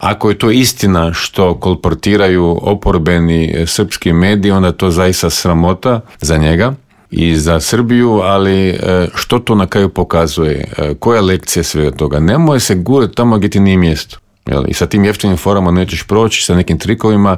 ZAGREB - Aktualne teme s naglaskom na početak konklave, izbor novog pape, ratne sukobe i situaciju u susjedstvu, prokomentirali smo u Intervjuu Media servisa s diplomatom i bivšim ministarom vanjskih poslova Mirom Kovačem.